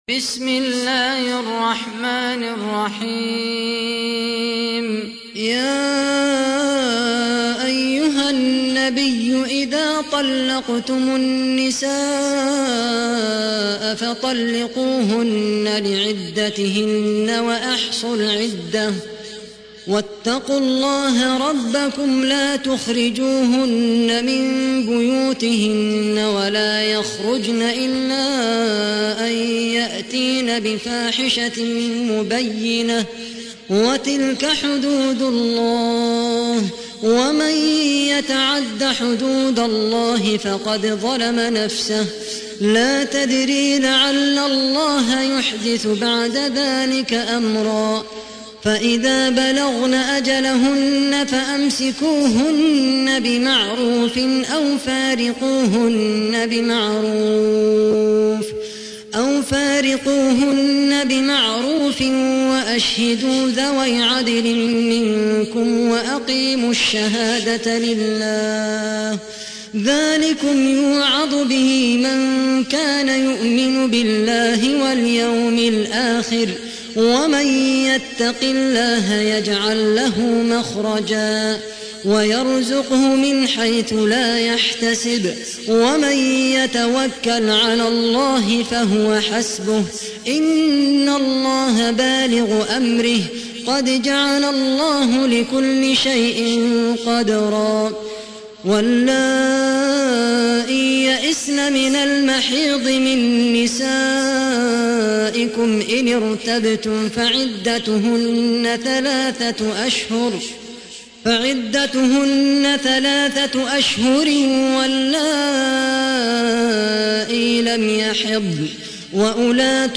تحميل : 65. سورة الطلاق / القارئ خالد القحطاني / القرآن الكريم / موقع يا حسين